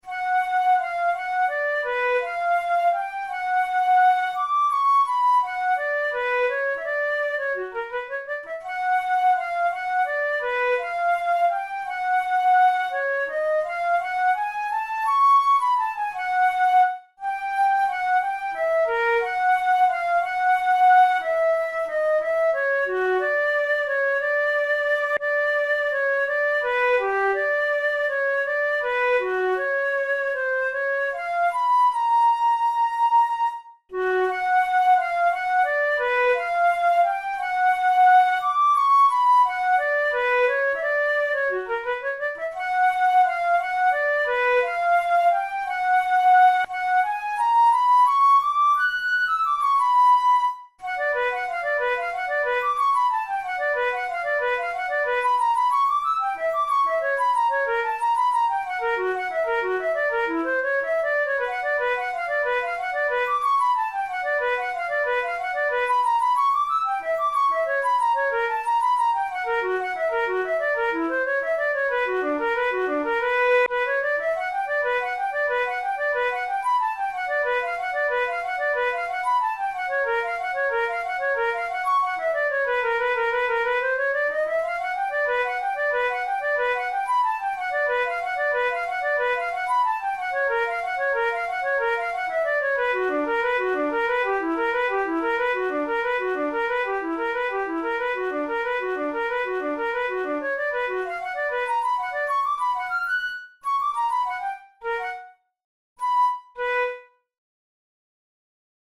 The first part is a melancholic moderate-tempo barcarole, while the second part is “more agitated” (“Più mosso”) but still graceful (“con grazia”).
Categories: Etudes Romantic Written for Flute Difficulty: intermediate